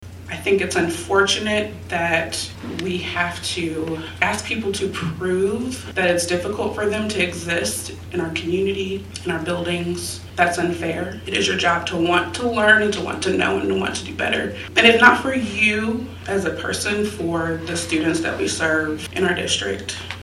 A packed crowd during Wednesday’s USD 383 meeting for public comment in regards to culturally responsive teaching and learning training.
Board President Jurdene Coleman, while fighting through tears, says it was hard to sit there and hear community members negate her experiences of being black.